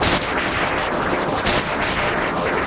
RI_RhythNoise_90-03.wav